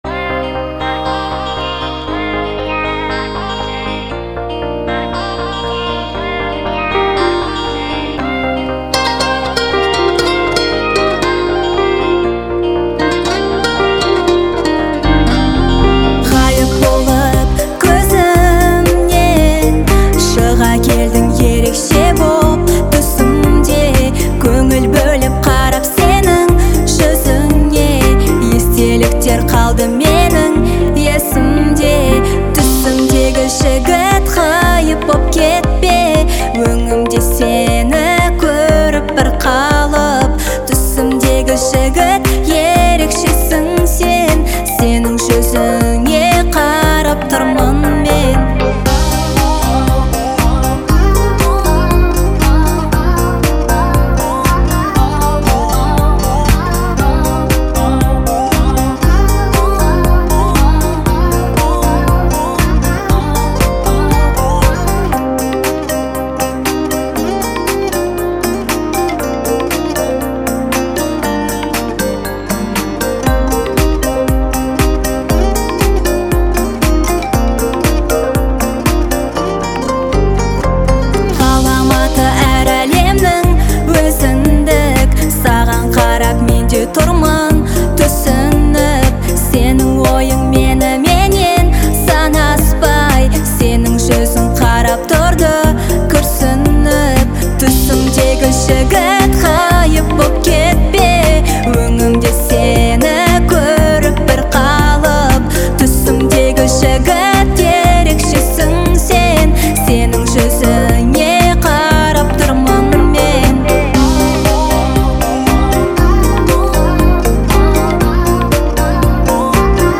Песня наполнена легким, воздушным звучанием